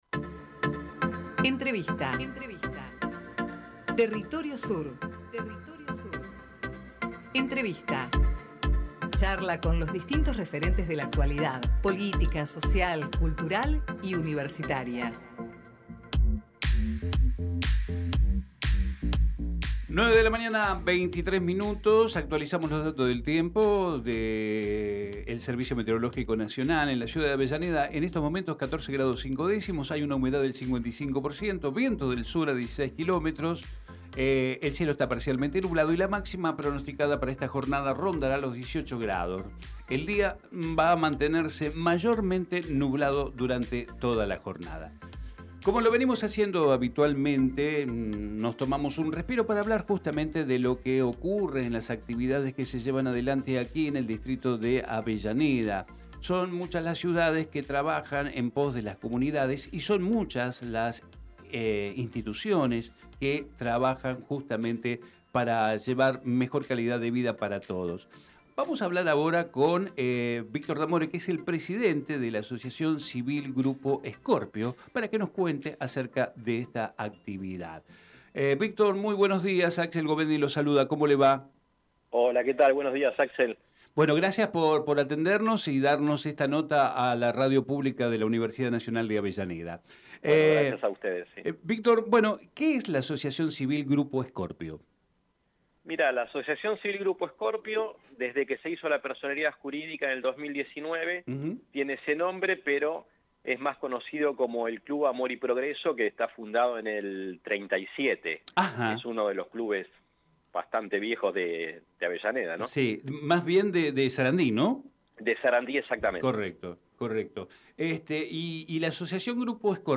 Compartimos la entrevista realizada en Territorio Sur